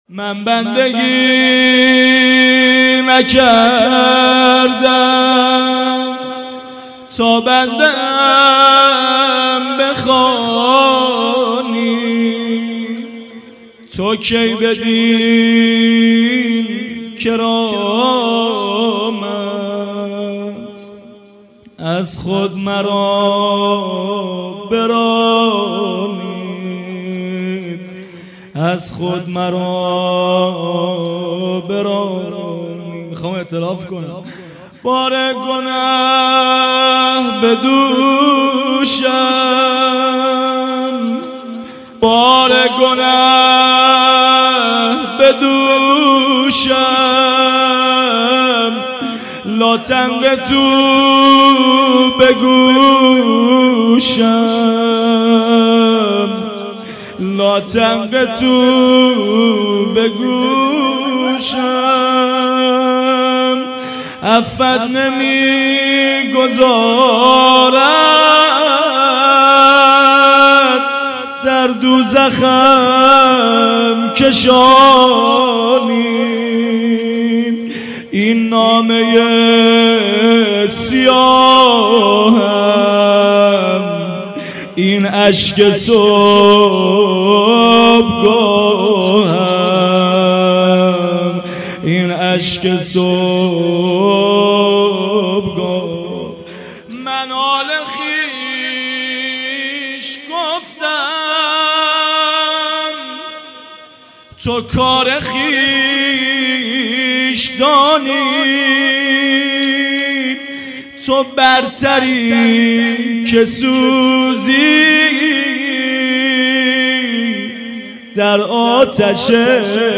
مراسم ماه رمضان